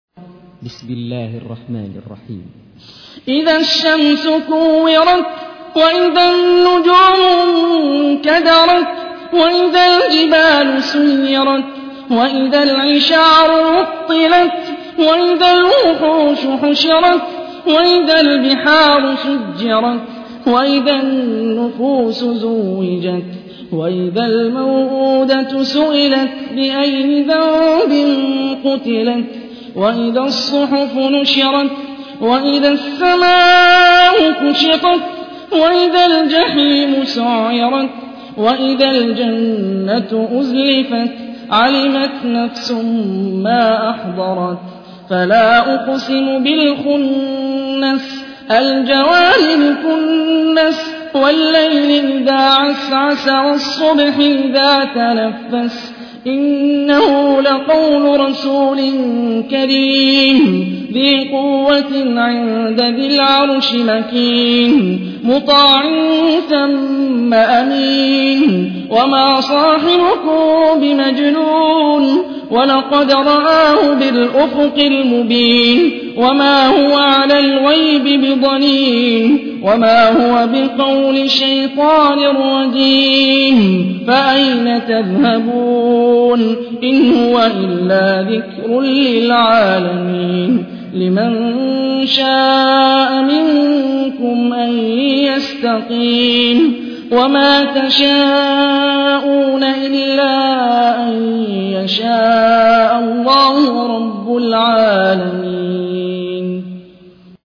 تحميل : 81. سورة التكوير / القارئ هاني الرفاعي / القرآن الكريم / موقع يا حسين